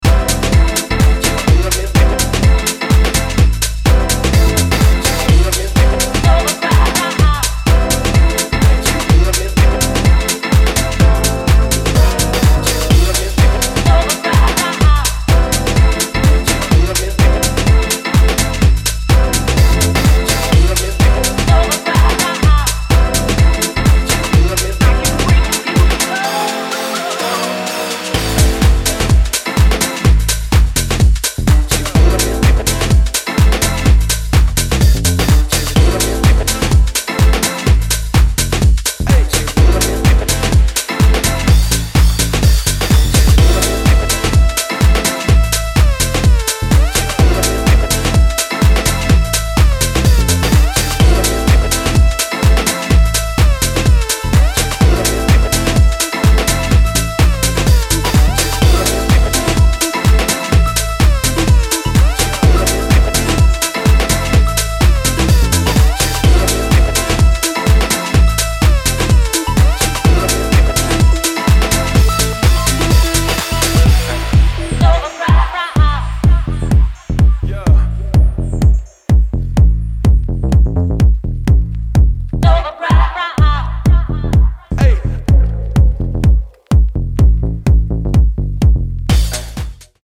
オルガンリフとスペーシーなリードの掛け合いが熱い
クラシカルでオーセンティック、そしてソウルフルなハウスのフィーリングをモダンに昇華する